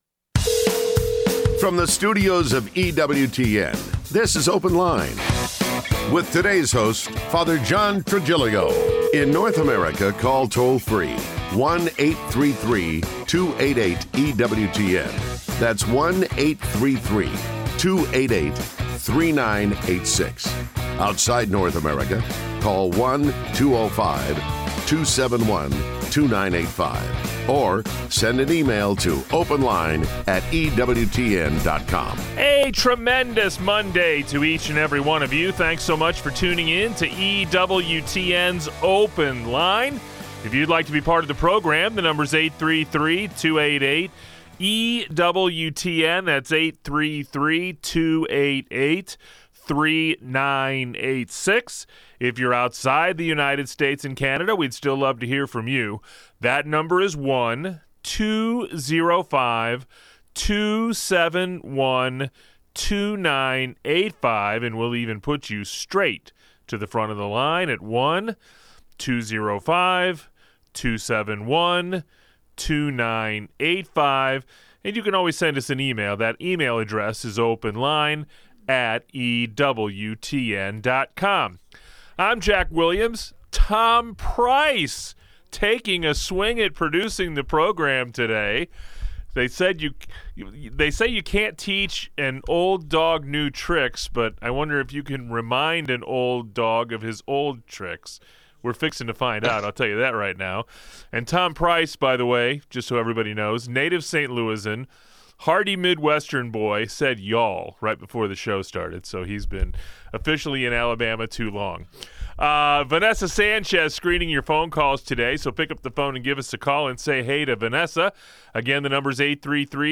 Call in: 833-288-EWTN (3986) | Apologetics